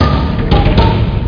TockTaTockTock.mp3